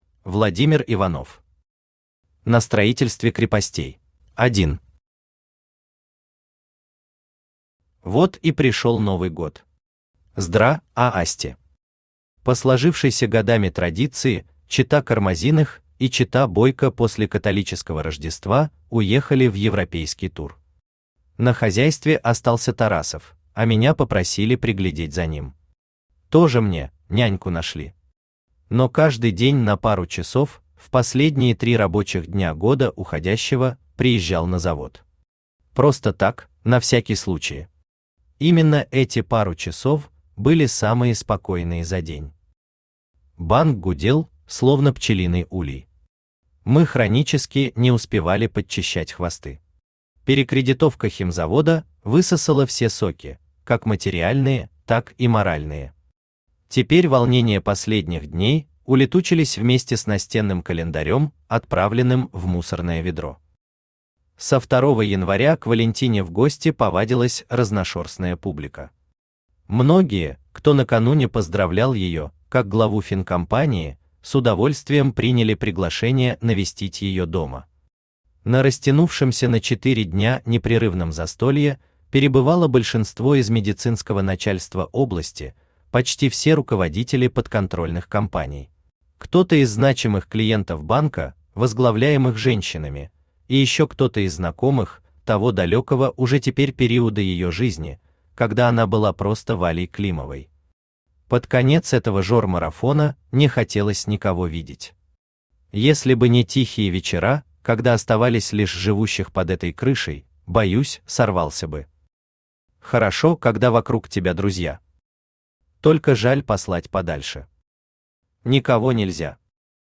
Аудиокнига На строительстве крепостей | Библиотека аудиокниг
Aудиокнига На строительстве крепостей Автор Владимир Иванович Иванов Читает аудиокнигу Авточтец ЛитРес.